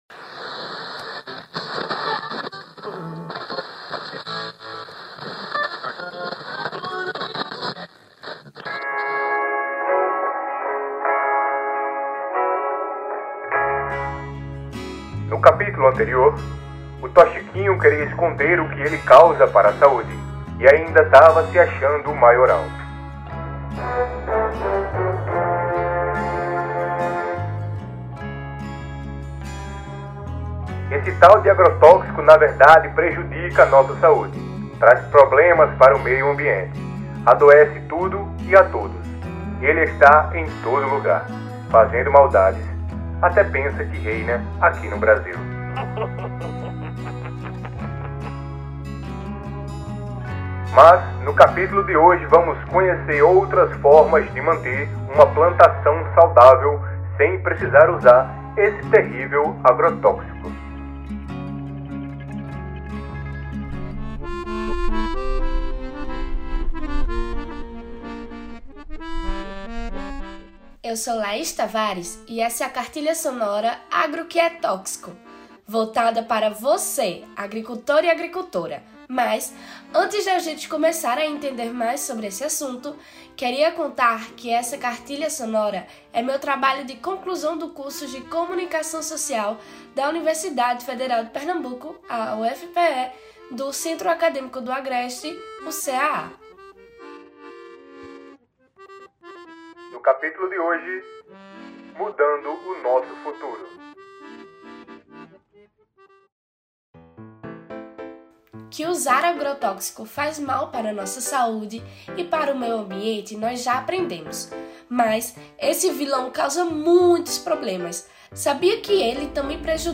Palavras-chave: Cartilha Sonora; Gêneros e formatosradiofônicos; Comunicação como direito humano; Agrotóxicos; MST
A cartilha aqui apresentada utiliza elementos da dramatização para trabalhar os riscos do uso de agrotóxicos, chegando a criar um personagem, o “Toxiquinho”.